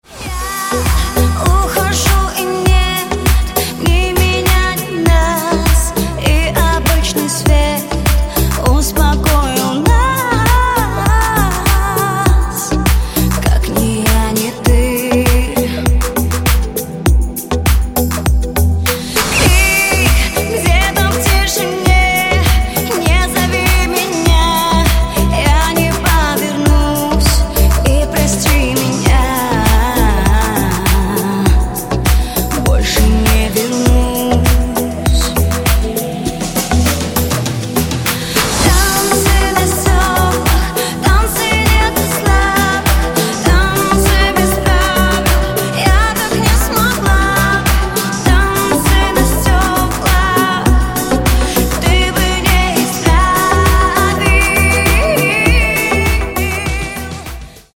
• Качество: 128, Stereo
женский вокал
deep house
dance
Electronic
club
vocal